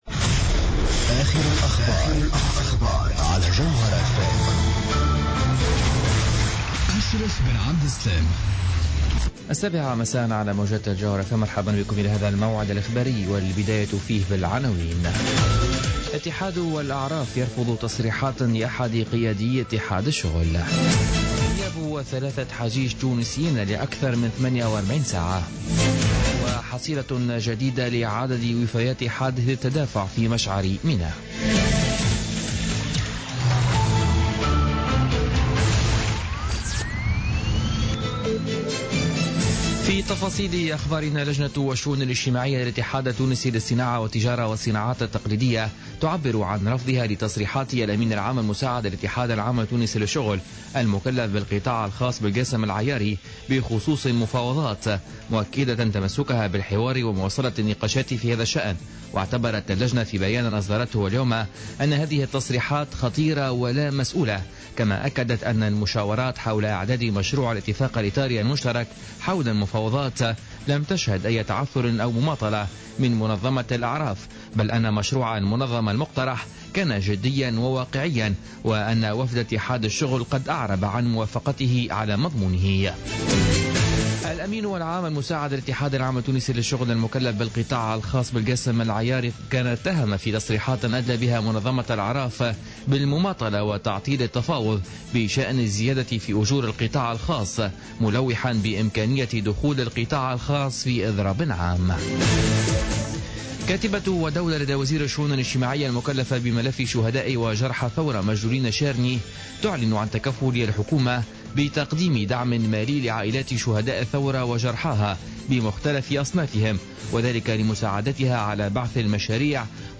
نشرة أخبار السابعة مساء ليوم السبت 26 سبتمبر 2015